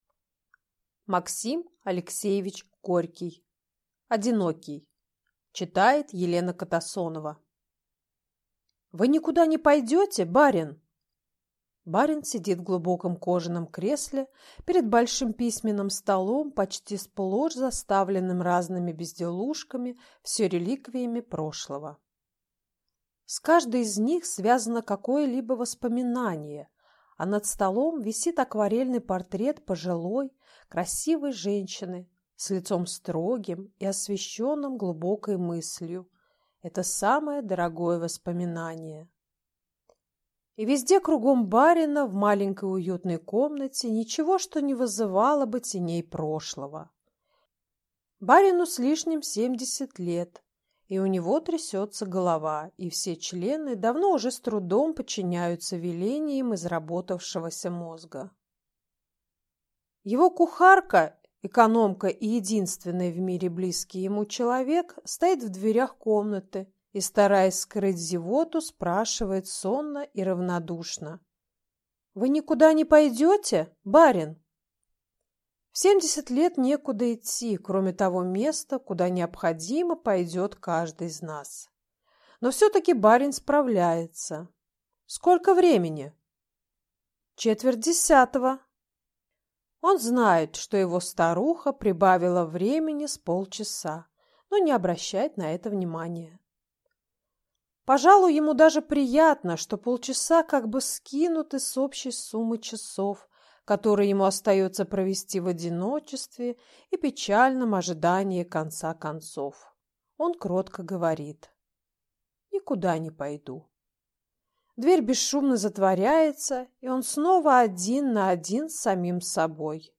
Aудиокнига Одинокий